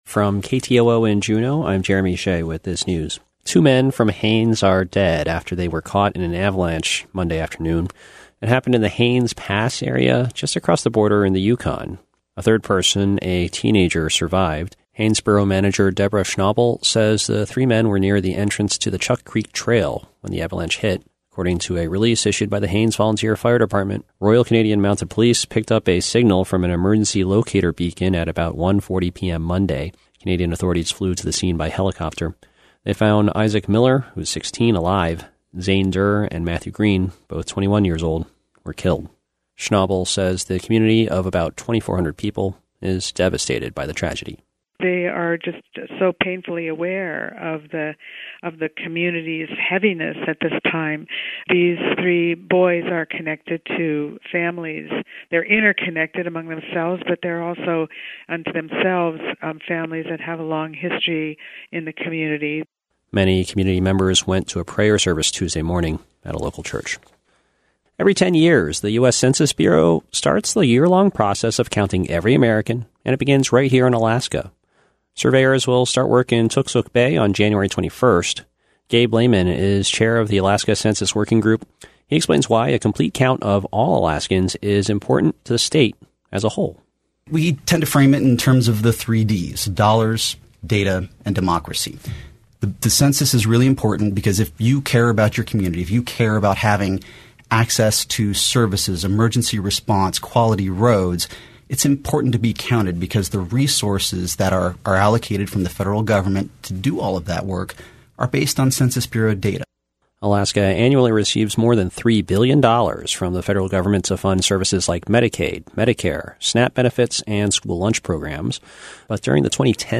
Newscast - Tuesday, Dec. 31, 2019